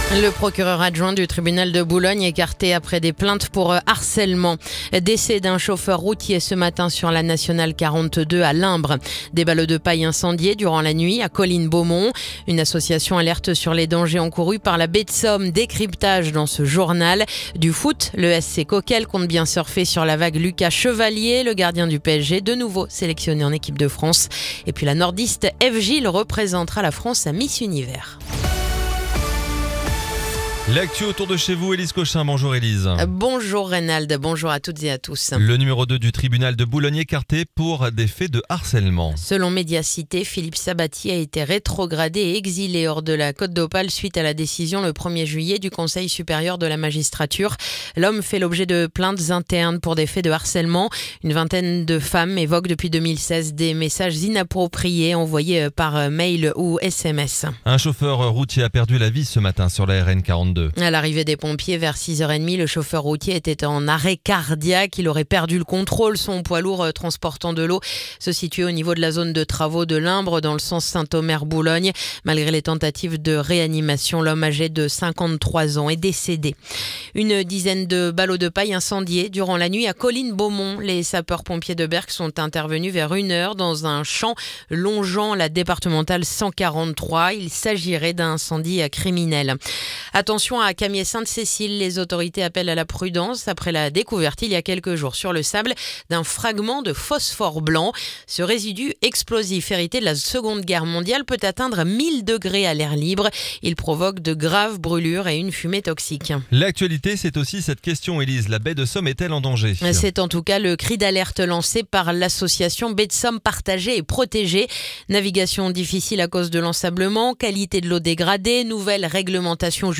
Le journal du jeudi 28 août